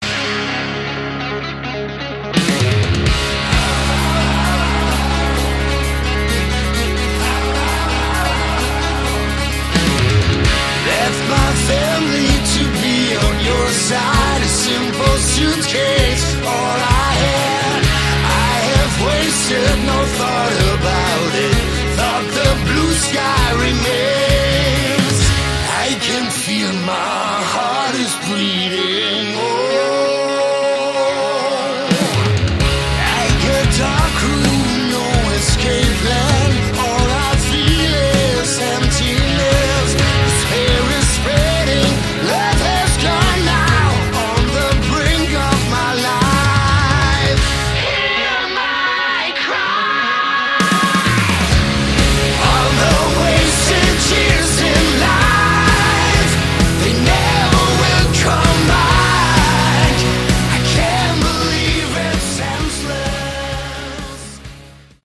Category: Hard Rock
guitars
drums
vocals
bass
keyboards